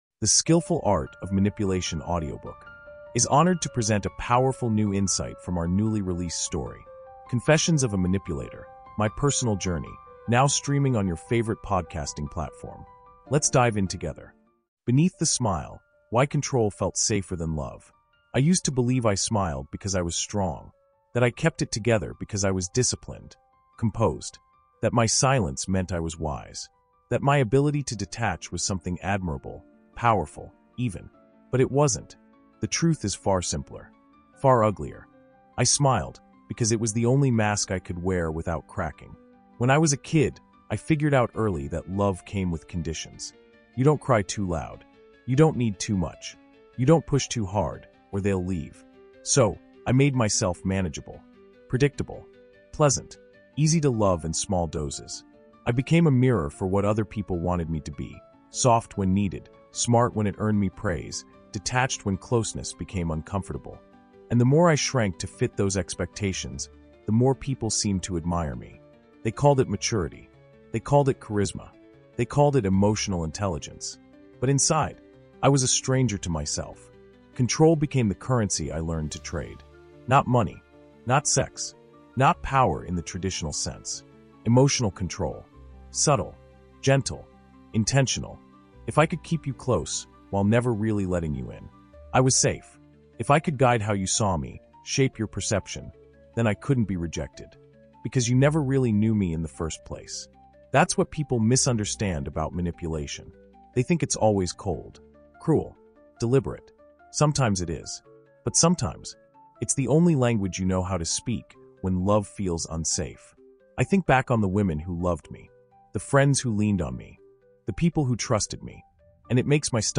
This emotionally charged reflection examines the psychological roots of manipulative behavior—not as a villainous impulse, but as a desperate strategy for survival. Told through a blend of haunting narration, introspective pauses, and confessional clarity, this insight pulls apart the core question: Can someone who’s never felt safe learn how to love without control?